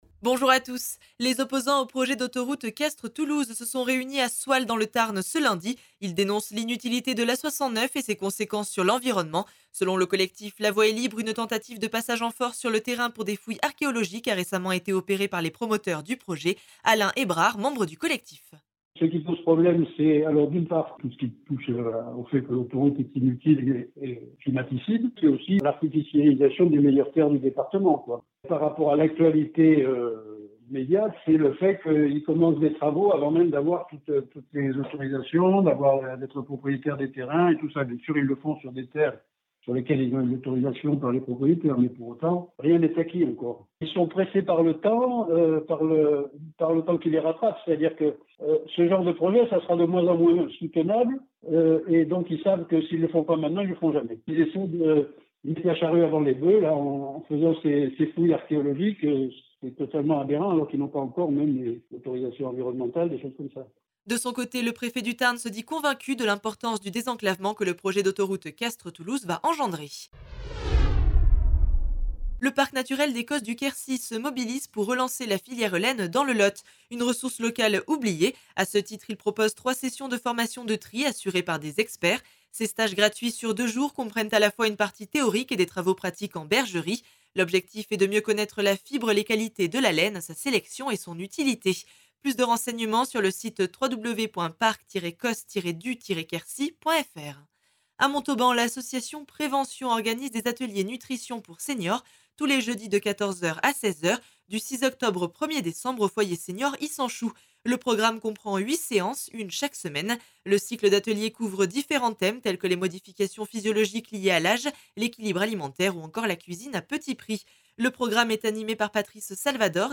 L’essentiel de l’actualité de la région Occitanie en 3 minutes. Une actualité centrée plus particulièrement sur les départements de l’Aveyron, du Lot, du Tarn et du Tarn & Garonne illustrée par les interviews de nos différents services radiophoniques sur le territoire.